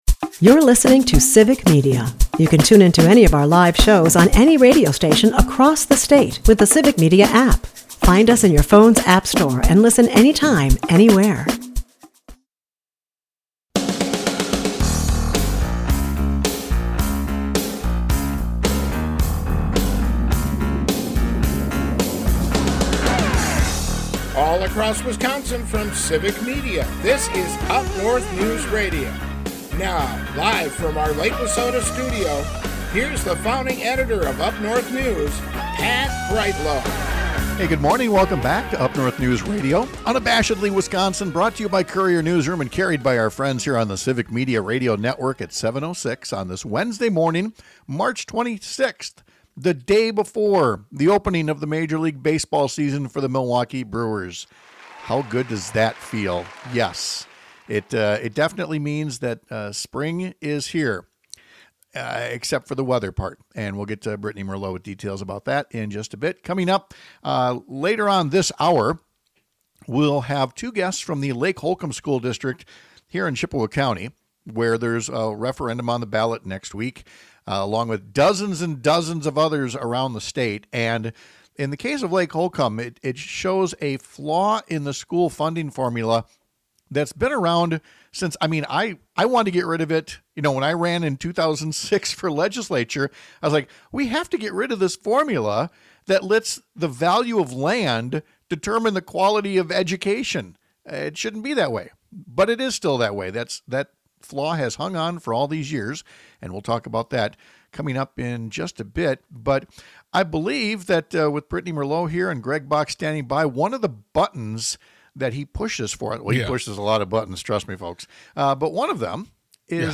Why are we still using land value to determine whether kids get a good education or not? We’ll talk to guests from Lake Holcombe, where the school district could shut down if a referendum fails to make up what’s lost in a terrible formula.